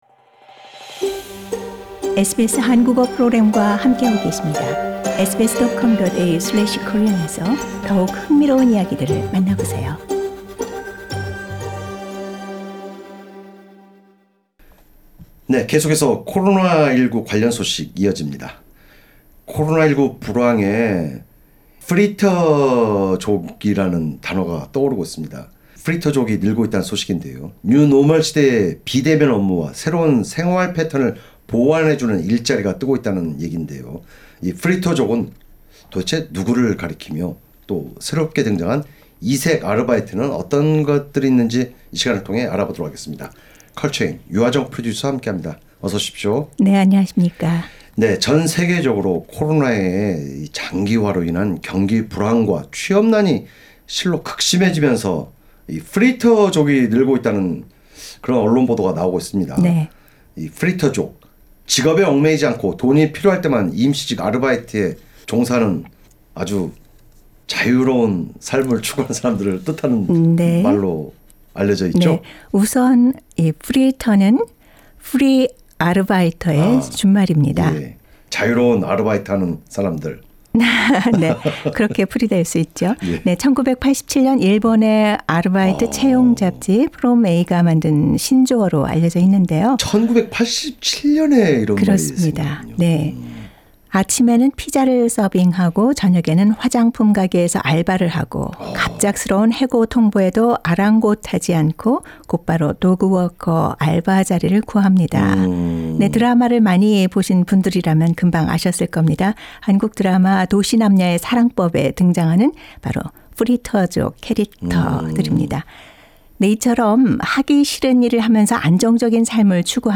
호주 국민 5분의 1 이용 호주, 관광 요식업 종사 해외 유학생 주 20시간 근무 제한 폐지 진행자: 전 세계적으로 코로나의 장기화로 인한 경기 불황과 취업난이 극심해지면서 프리터(Freeter)족이 늘고 있는 추세인데, '프리터족' 이란 직업에 얽매이지 않고 돈이 필요할 때만 임시직 아르바이트에 종사하는 사람들을 뜻하는 말로 알려져 있죠?